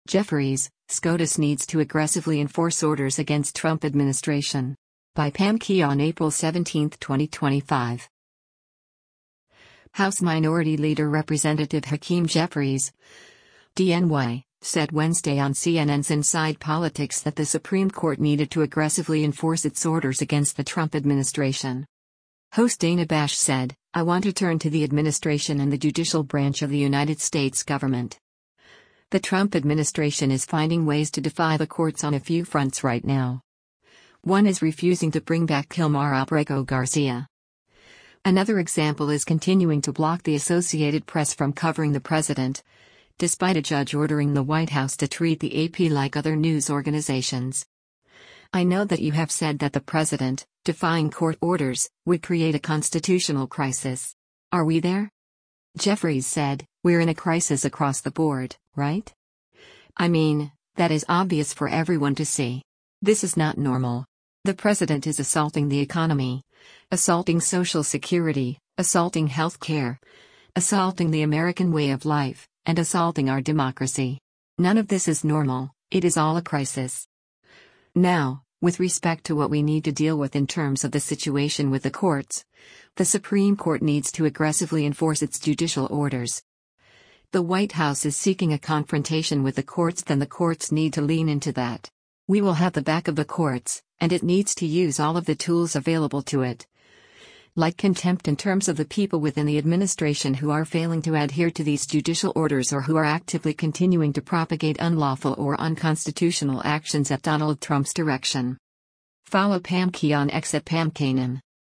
House Minority Leader Rep. Hakeem Jeffries (D-NY) said Wednesday on CNN’s “Inside Politics” that the Supreme Court needed to “aggressively” enforce its orders against the Trump administration.